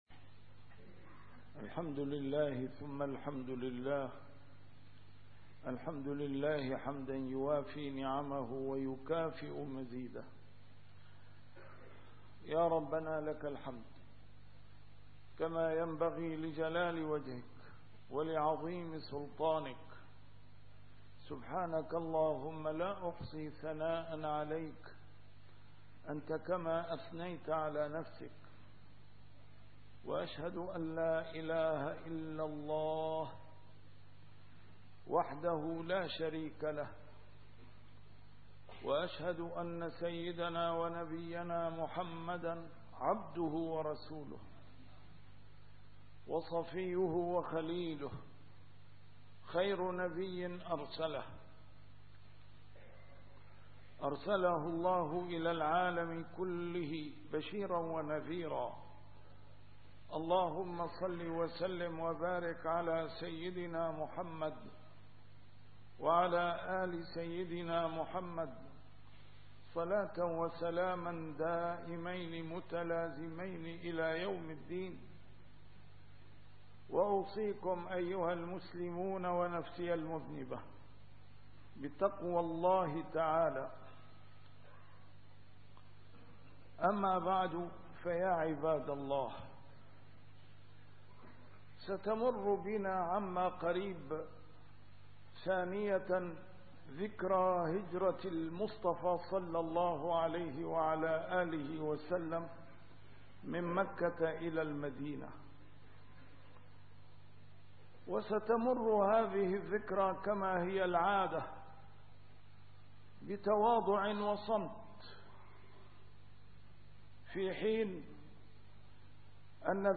A MARTYR SCHOLAR: IMAM MUHAMMAD SAEED RAMADAN AL-BOUTI - الخطب - عندما يفتي الدجالون بالبقاء في دار الكفر